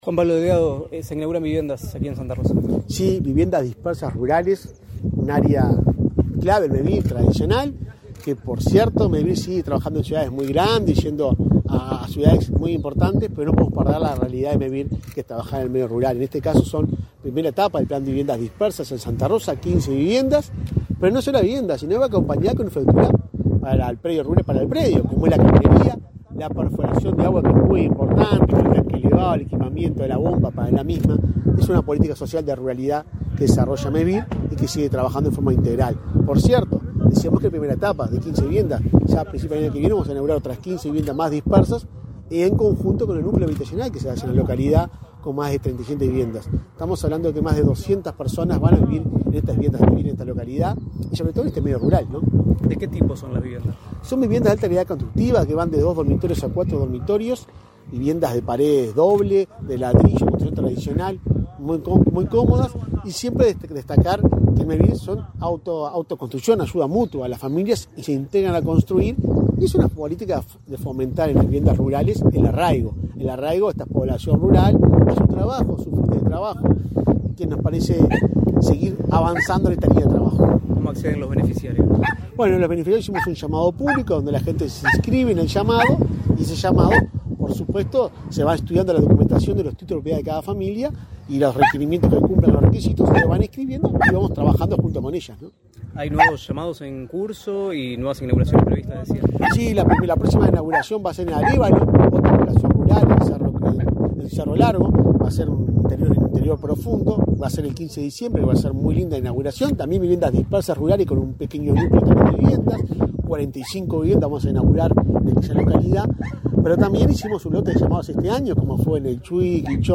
Entrevista al presidente de Mevir, Juan Pablo Delgado
Entrevista al presidente de Mevir, Juan Pablo Delgado 29/11/2022 Compartir Facebook X Copiar enlace WhatsApp LinkedIn Tras participar en la inauguración de viviendas en terrenos particulares en el área urbana y rural de Santa Rosa, departamento de Canelones, este 29 de noviembre, el presidente de Mevir realizó declaraciones a Comunicación Presidencial.